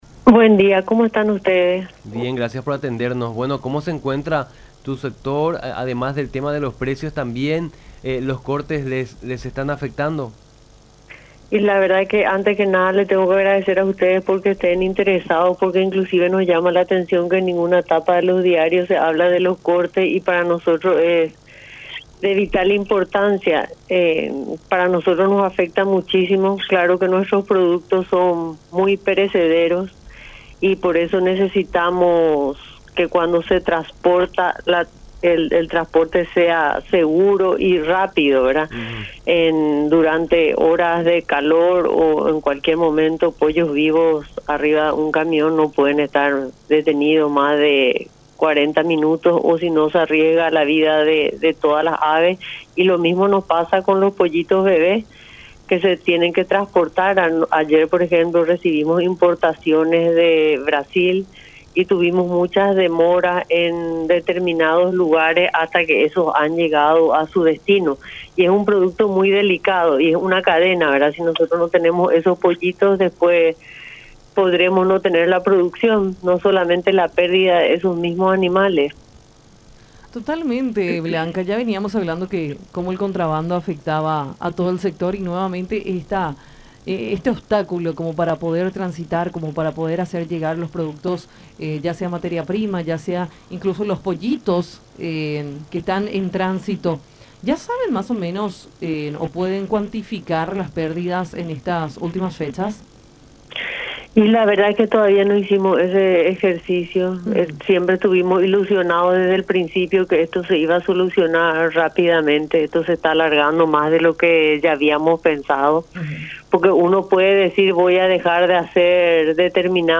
en conversación con Nuestra Mañana por La Unión.